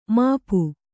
♪ māpu